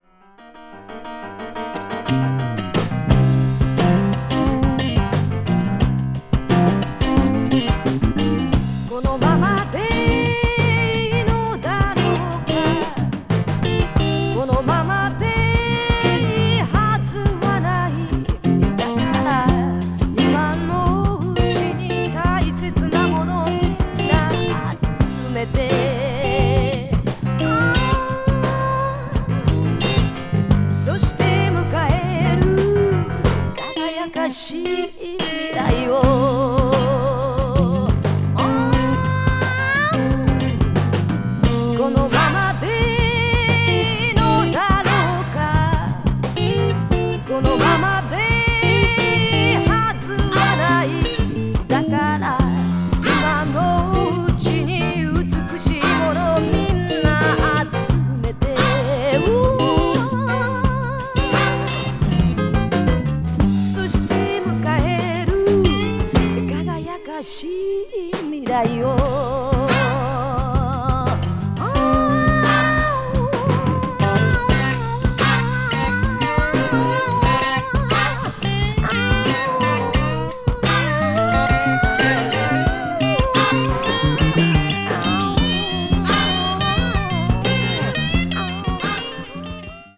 media : EX/EX(わずかにチリノイズが入る箇所あり)
blues rock   classic rock   funky rock   rare groove